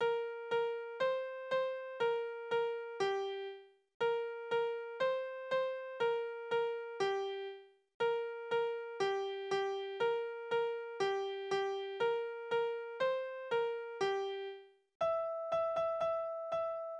Spielverse: Ringel, ringel Rosenkranz
Tonart: B-Dur
Taktart: 2/4
Tonumfang: große Sexte